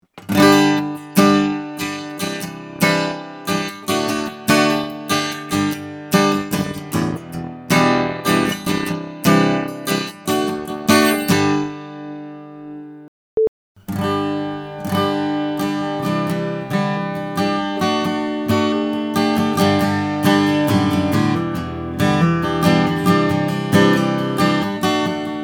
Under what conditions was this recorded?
Perfect tone Fades loss after 4 seconds I am recording tracks and it sounds perfect and after 4 seconds i loose all quality in my recording.